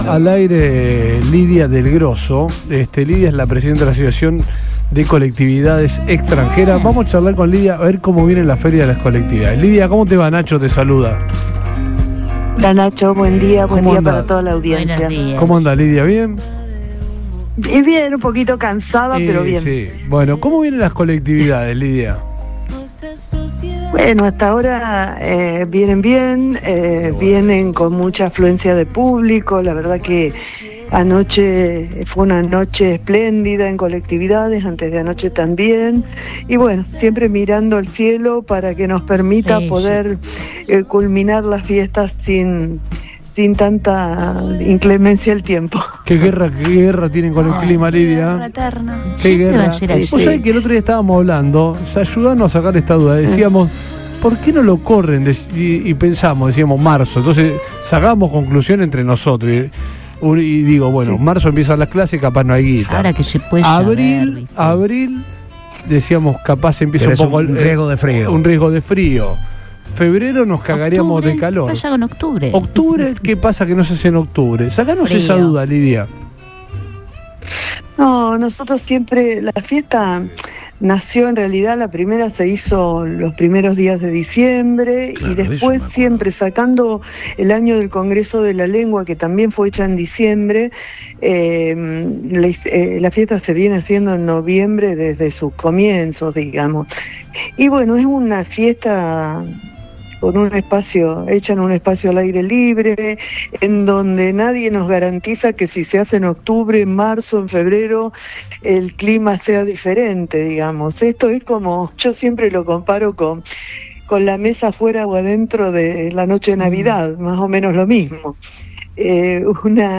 dialogó con el programa Todo Pasa de Radio Boing 97.3